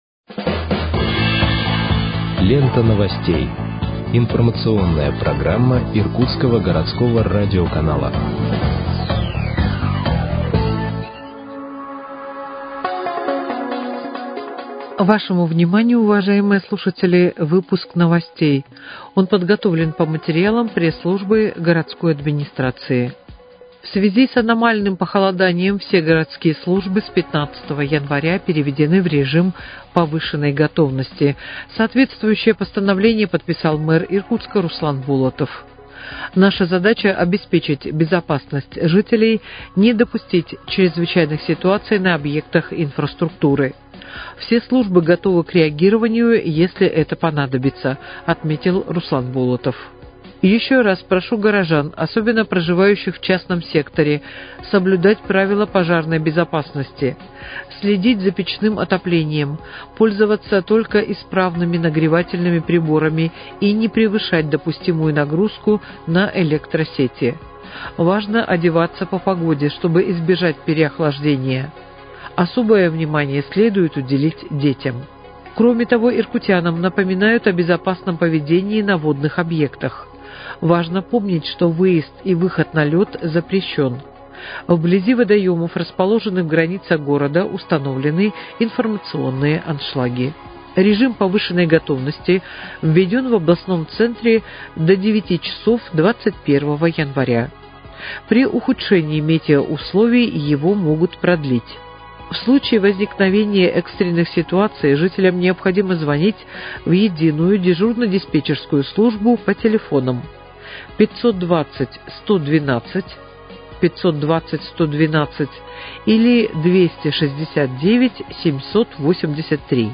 Выпуск новостей в подкастах газеты «Иркутск» от 20.01.2026 № 1